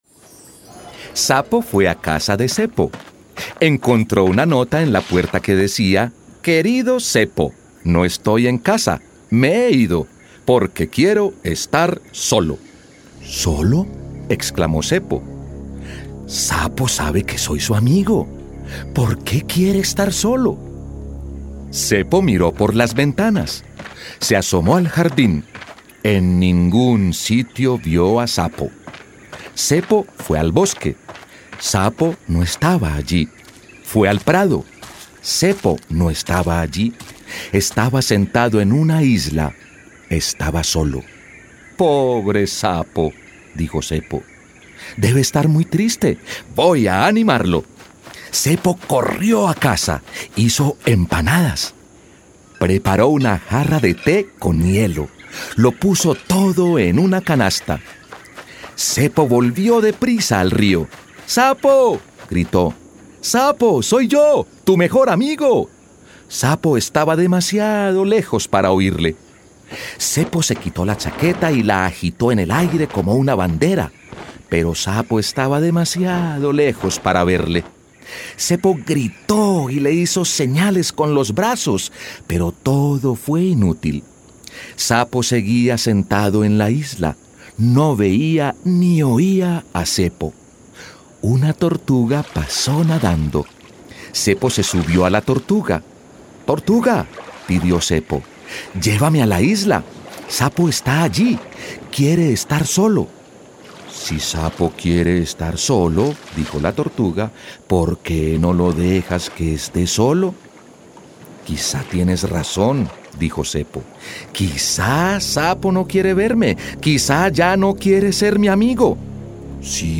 Masculino